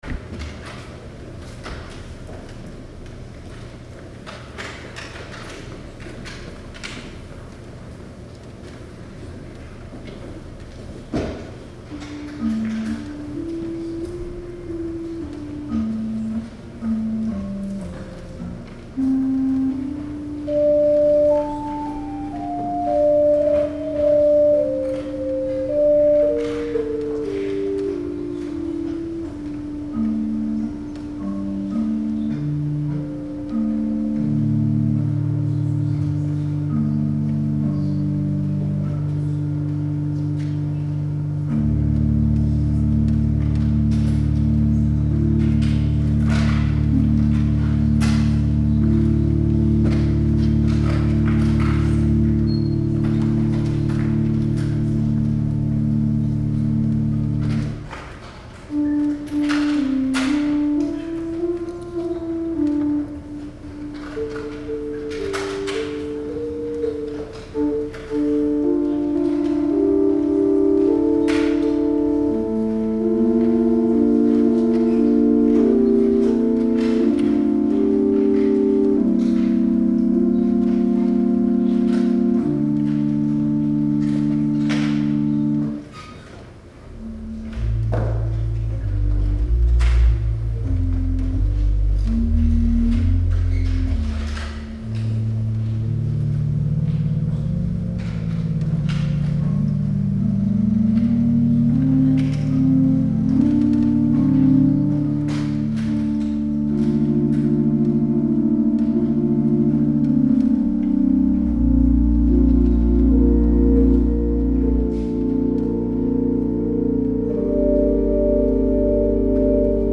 Music from November 24, 2019 Sunday Service